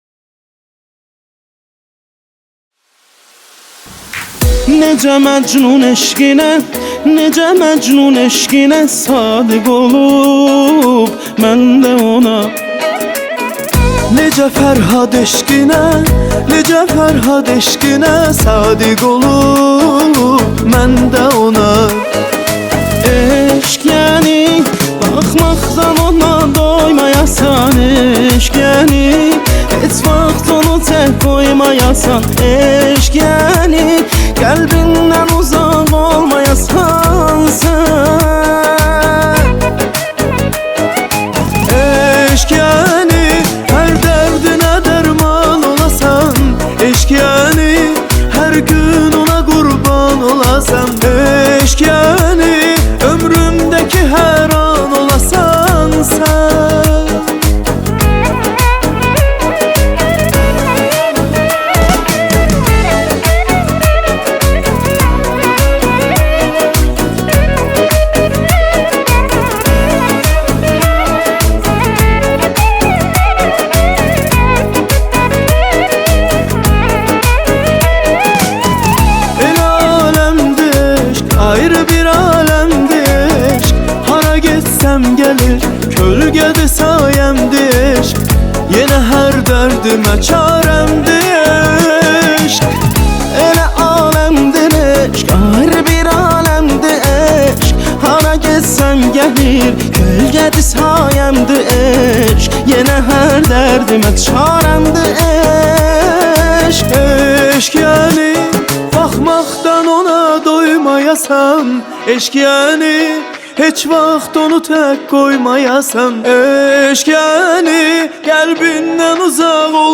En Güzel Pop Müzikler Türkçe + Yeni şarkılar indir